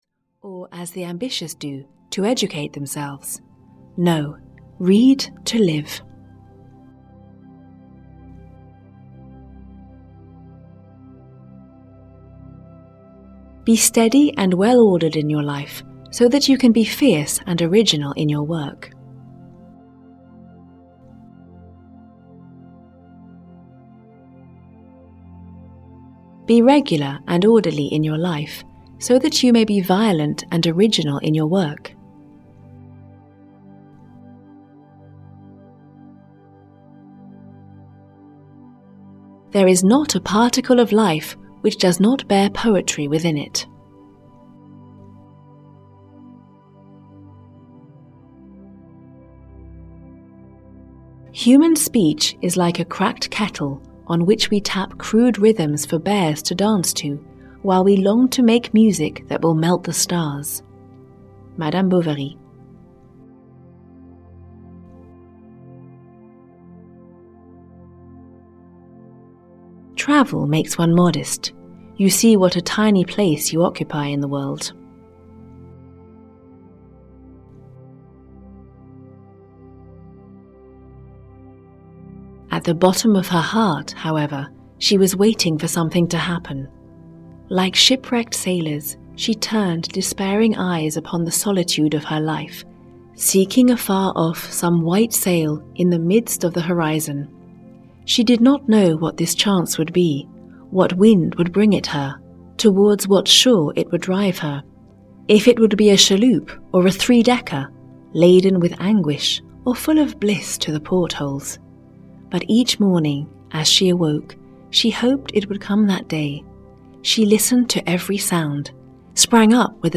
100 Quotes by Gustave Flaubert (EN) audiokniha
Ukázka z knihy